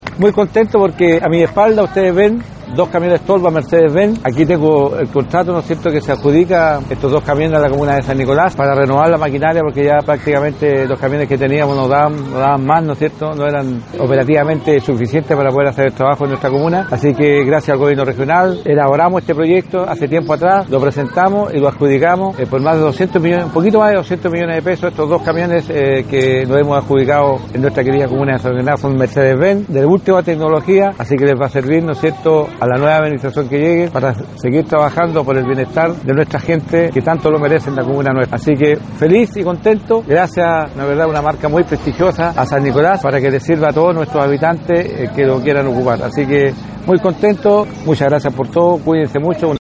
Cuna-alcalde-Victor-Hugo-Rice-Camiones-Tolva-2.mp3